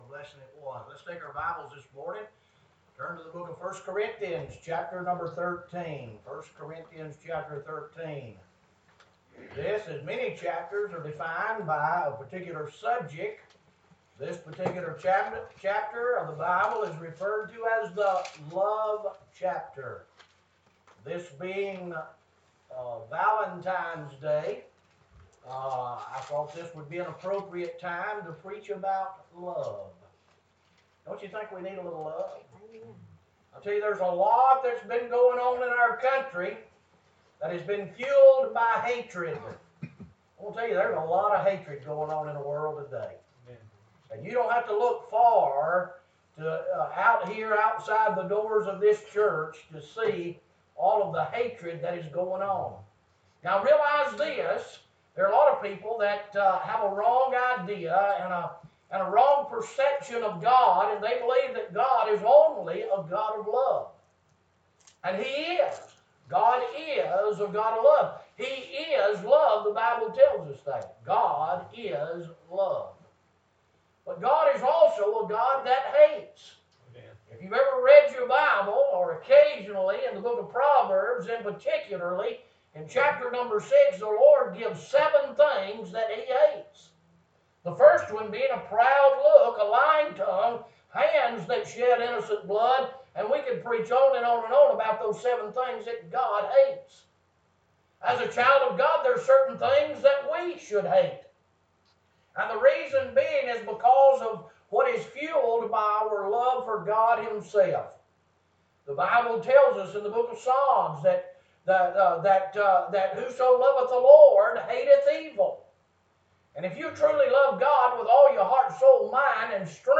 Morning Sermon
Livestream Recording